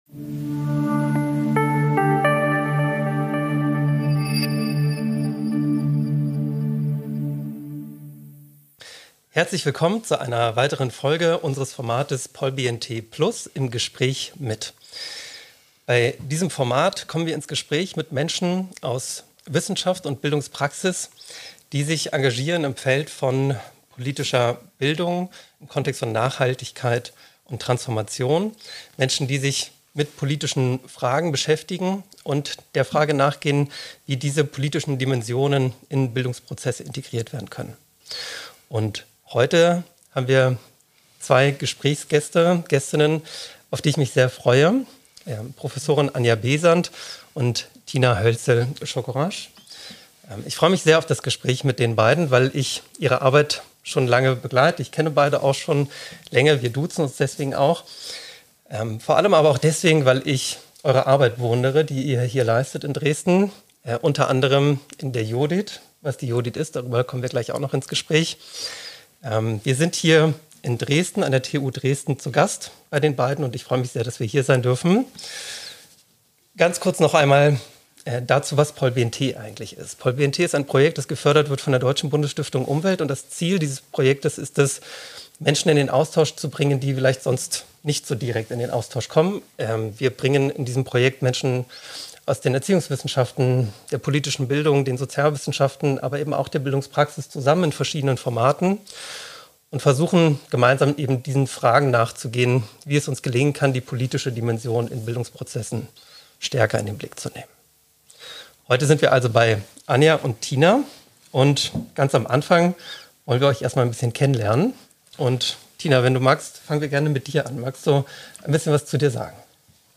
Daher werden wir zukünftig in recht spontanen internen Runden zusammenfinden und uns gemeinsam über Aktuelles austauschen.